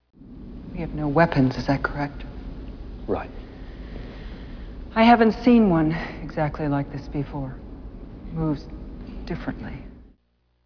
Audio clips in wav formatSOUNDSStarving actors speak out